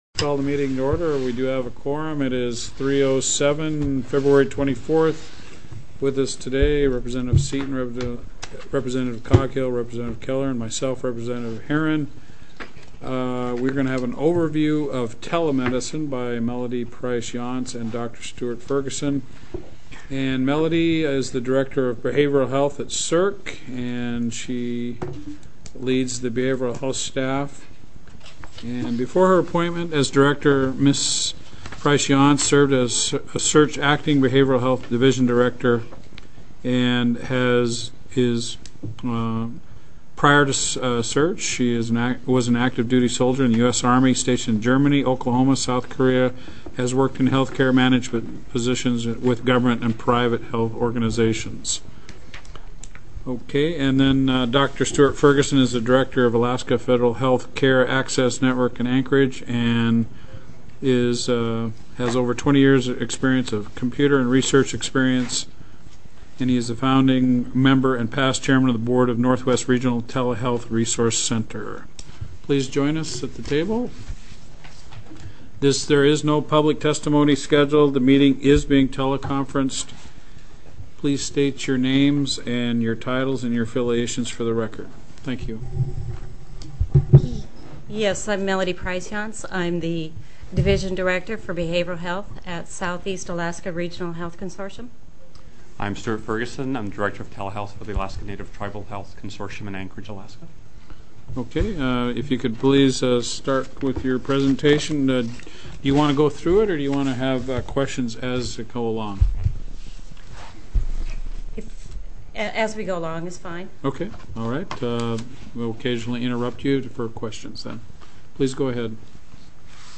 02/24/2009 03:00 PM House HEALTH & SOCIAL SERVICES